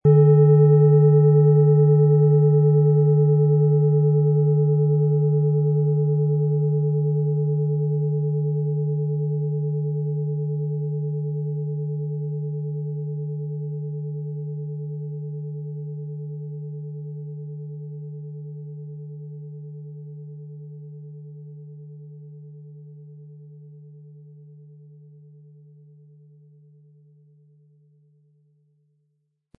Planetenton 1
Saturn
Planetenschale®
Im Audio-Player - Jetzt reinhören hören Sie genau den Original-Ton der angebotenen Schale.
Lassen Sie sich von der Kraft der Saturn verzaubern, in dem Sie sie mit dem kostenfrei beigelegtem Klöppel sanft anspielen.
MaterialBronze